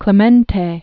(klə-mĕntā), Roberto Walker 1934-1972.